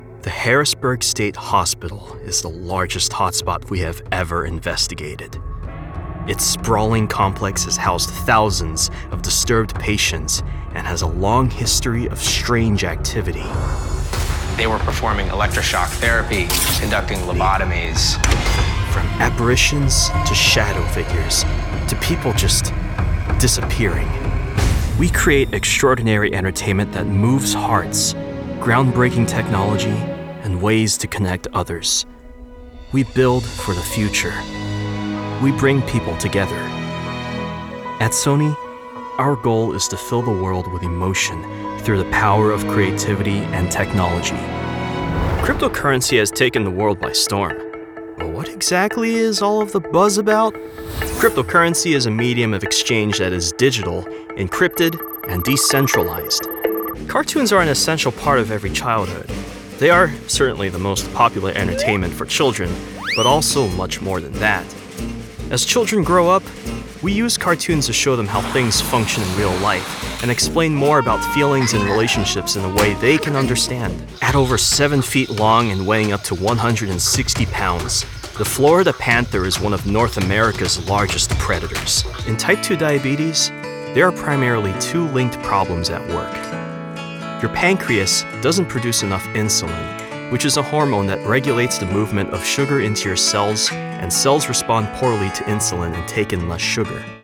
Teenager, Young Adult, Adult, Mature Adult
NARRATION 😎
broadcast level home studio